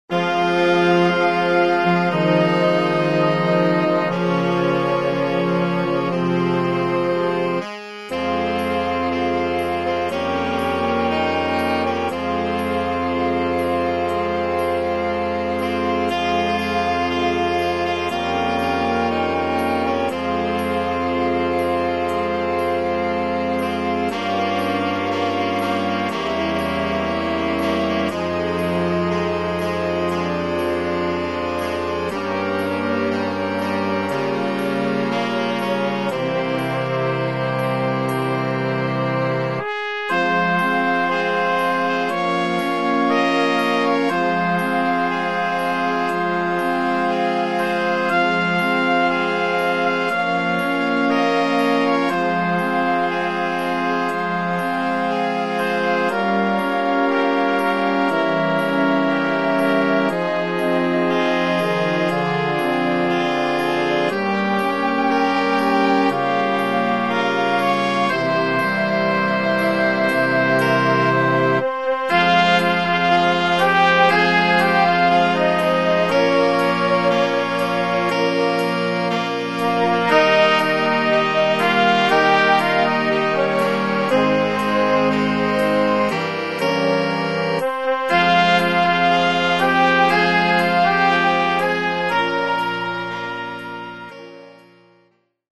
pasyjna